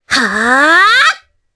Requina-Vox_Casting4_jp.wav